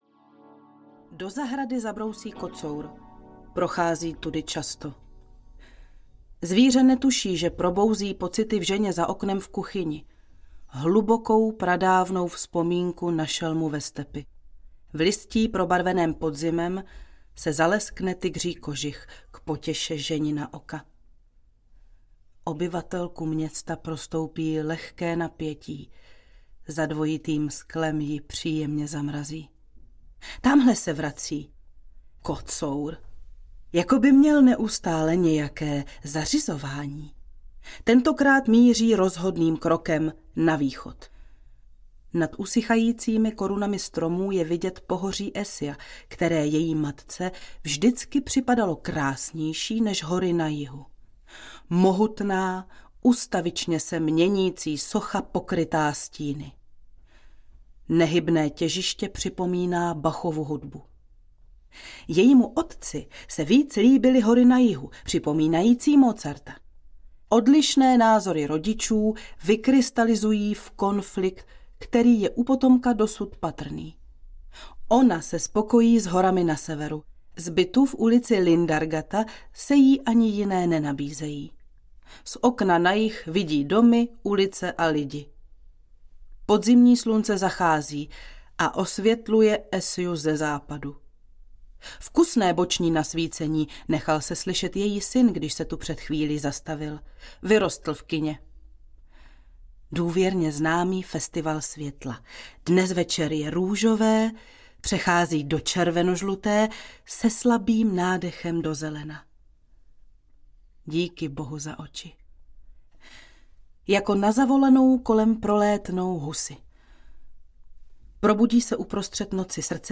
Dvojité sklo audiokniha
Ukázka z knihy
dvojite-sklo-audiokniha